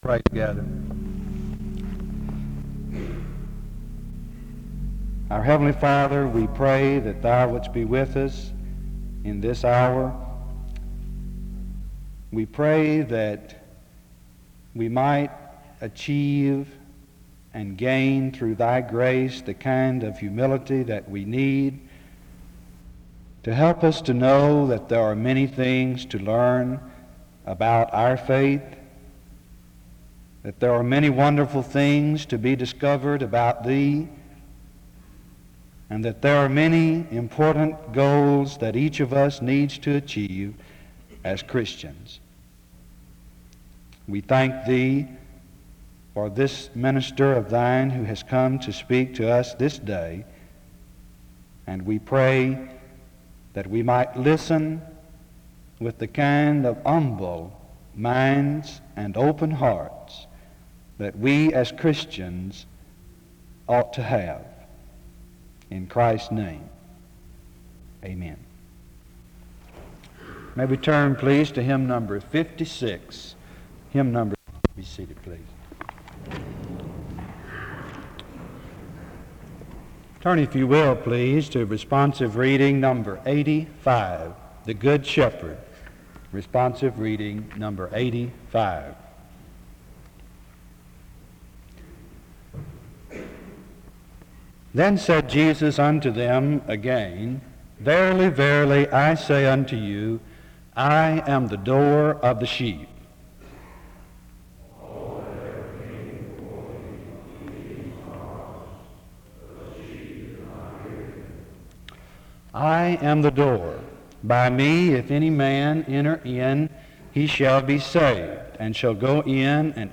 The service begins with a word of prayer from 0:00-1:08. A responsive reading takes place from 1:20-3:49. An introduction to the speaker is given from 3:55-4:39.